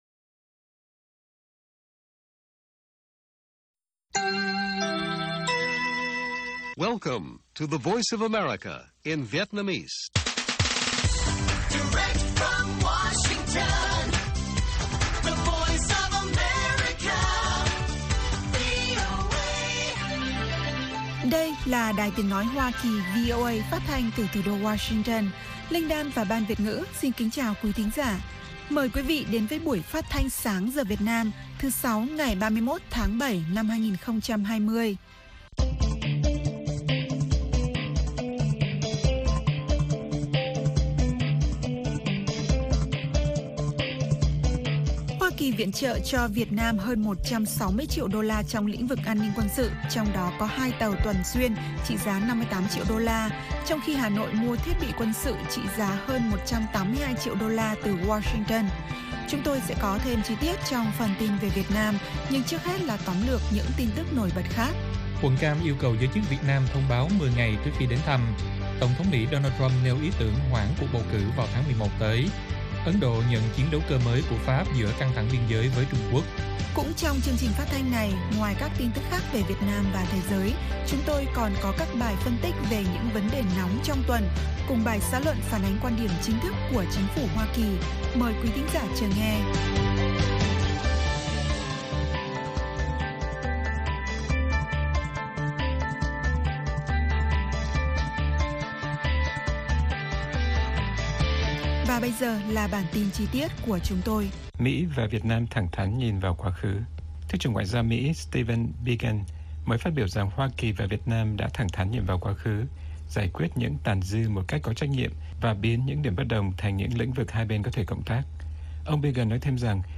Bản tin VOA ngày 31/7/2020